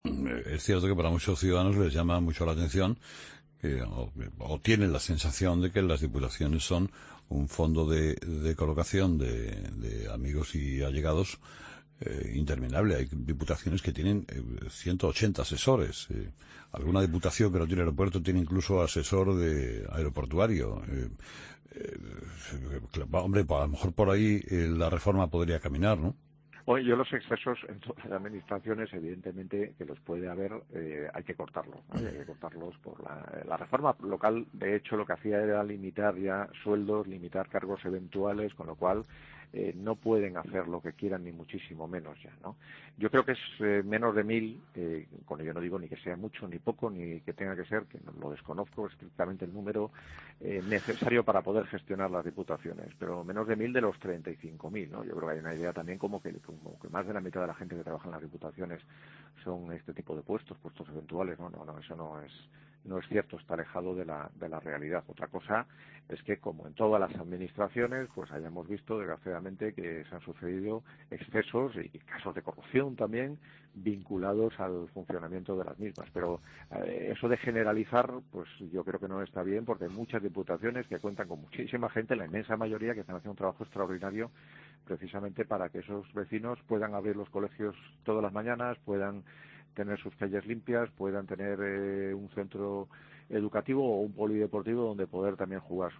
Escucha al entonces alcalde de Santander, en 'Herrera en COPE', el 16 de marzo de 2016